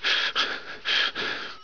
1 channel
pelfgasp.wav